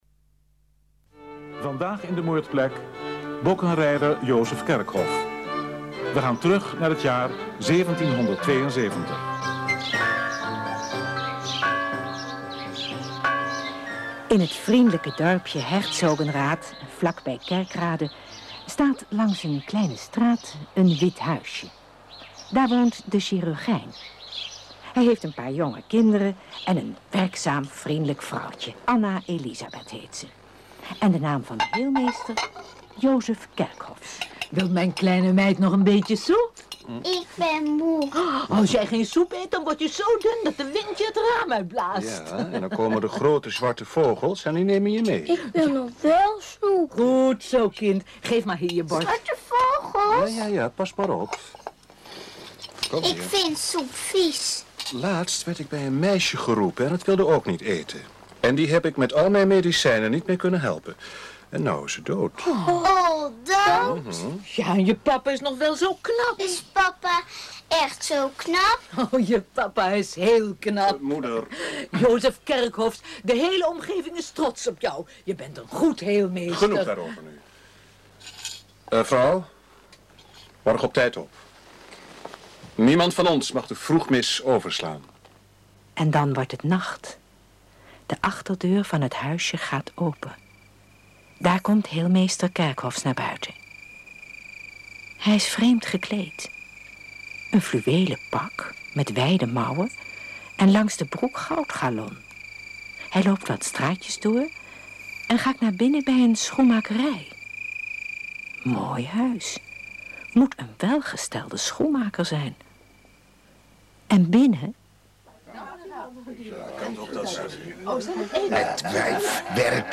In elke aflevering wordt in een kort hoorspel de moord op een historische figuur beschreven